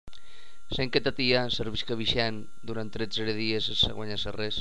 1.2.3.6 Castellonenc sud
CASTLSUD.mp3